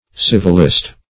civilist - definition of civilist - synonyms, pronunciation, spelling from Free Dictionary Search Result for " civilist" : The Collaborative International Dictionary of English v.0.48: Civilist \Civ"il*ist\, n. A civilian.